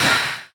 sounds / mob / phantom / hurt1.ogg
hurt1.ogg